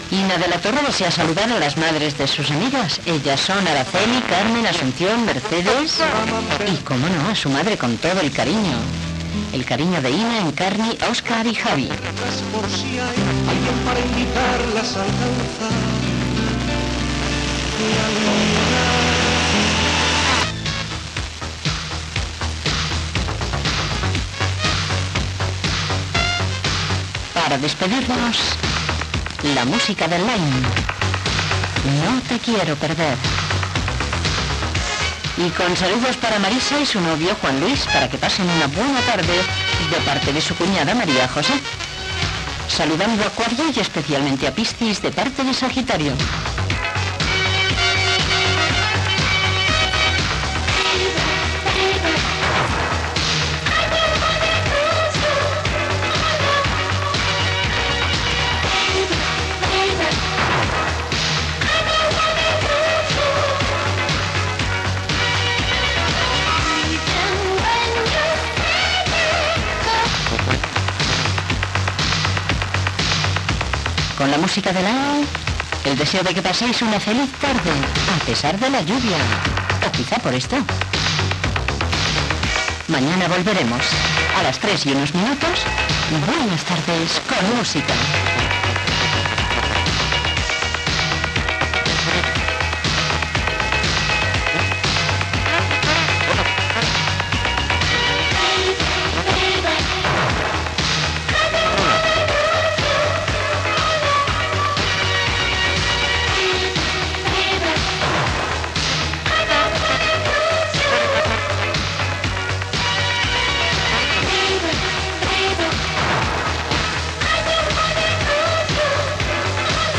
Dedicatòries musicals i notícies de RCE (Felipe González marxa per assistir al funeral de Konstantín Txernenko).
FM